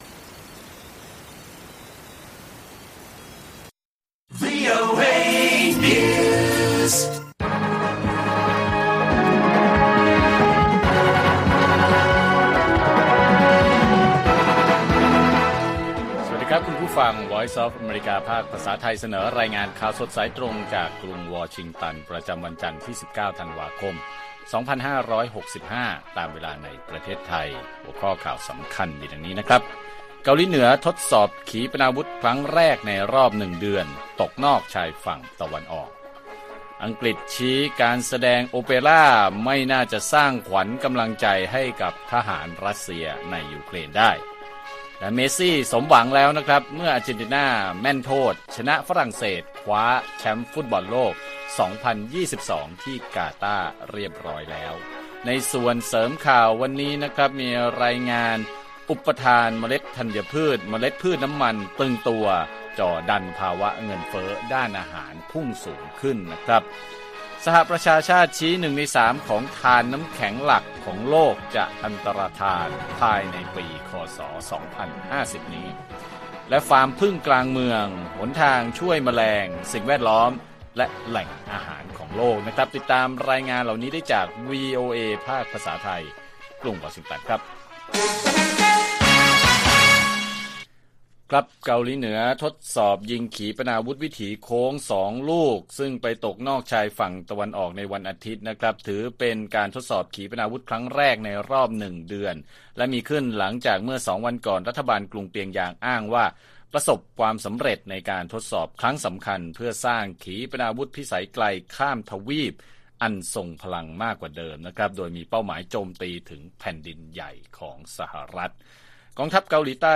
ข่าวสดสายตรงจากวีโอเอ ภาคภาษาไทย 6:30 – 7:00 น. วันจันทร์ที่ 19 ธ.ค. 2565